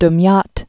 (dm-yät)